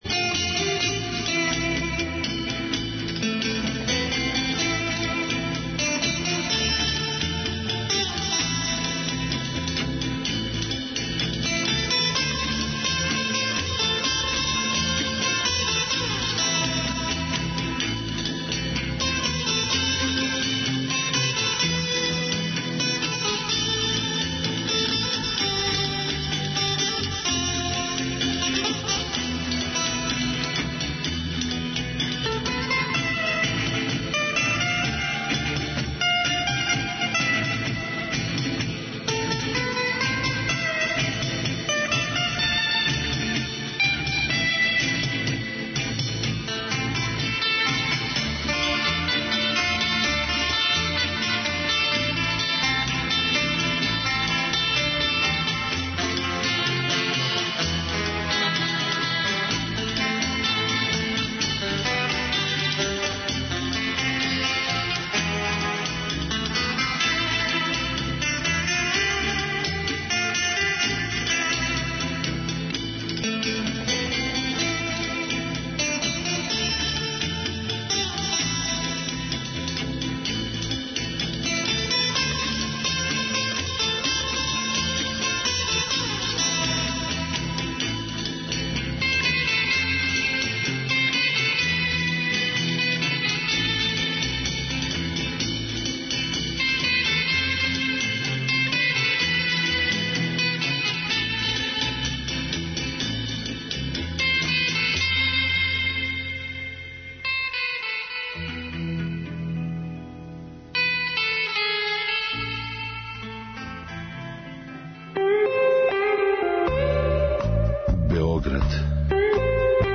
Свако вече, осим суботе, од поноћи на Двестадвојци у емисији Кажи драгичка гост изненађења! Музички гост се, у сат времена програма, представља слушаоцима својим ауторским музичким стваралаштвом, као и музичким нумерама других аутора и извођача које су по њему значајне и које вам препоручују да чујете.